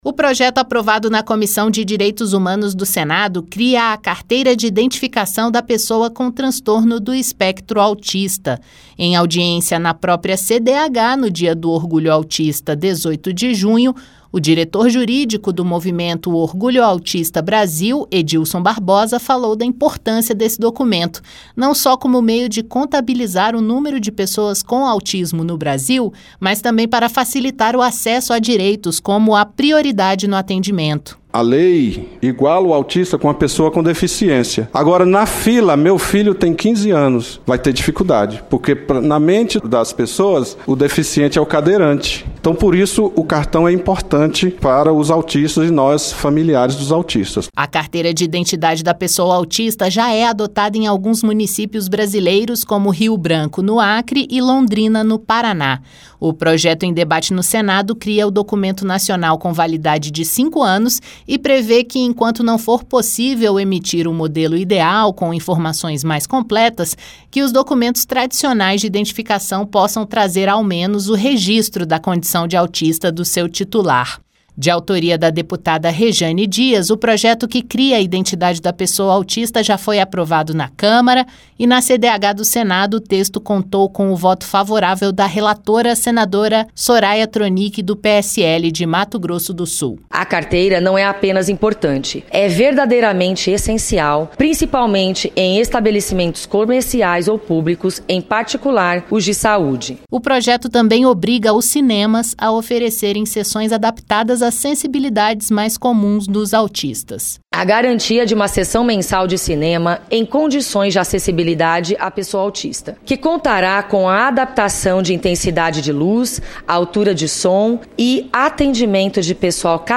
O projeto também obriga cinemas a oferecerem sessões adaptadas. A reportagem